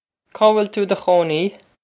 Caw will too ih duh khoh-nee?
This is an approximate phonetic pronunciation of the phrase.